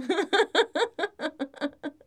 Gendered social sounds
female_giggle2.wav